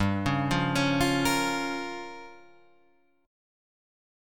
Gm7b5 chord